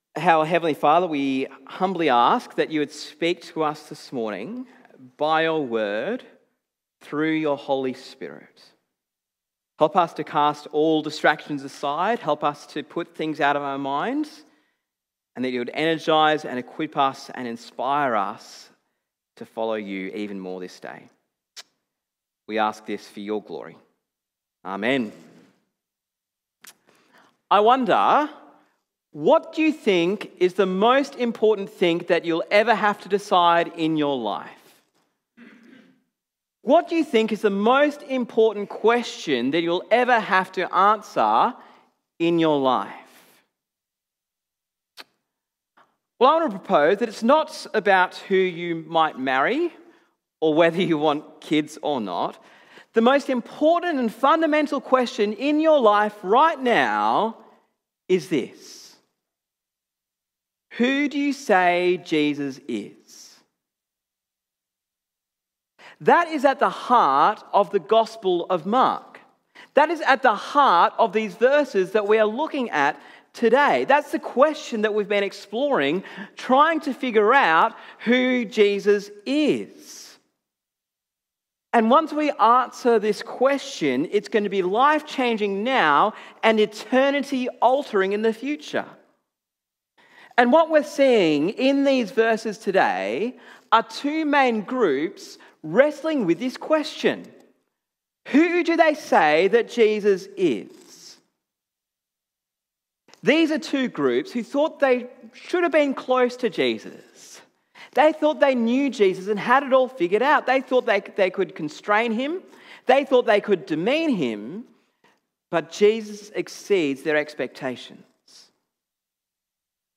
Sermon on Mark 3:20-35 - Stronger
Stronger This is the fourth sermon in our series in Mark Download Sermon Transcript and Questions Download Series resource Download Sermon Audio See Other Sermons in Series